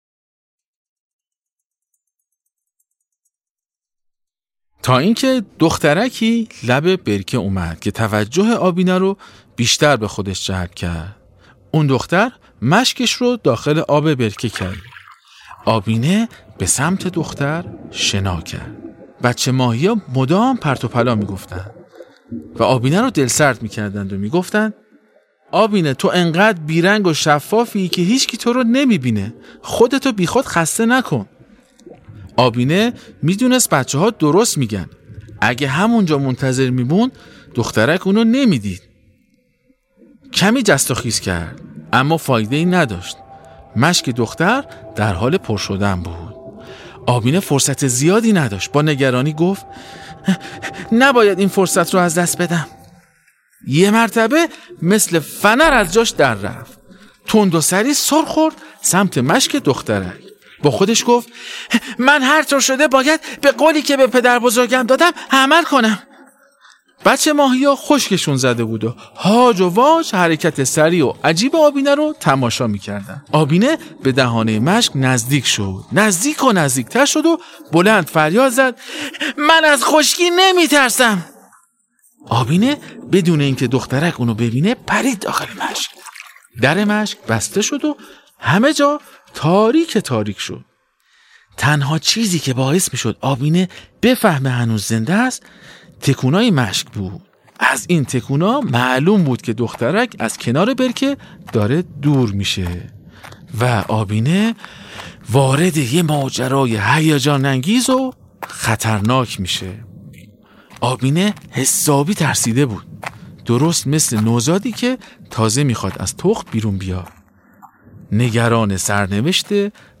داستان ماهی ای که از یه جریان خیلی مهم برامون صحبت میکنه. باهم قسمت پنجم از داستان صوتی آبینه رو بشنویم.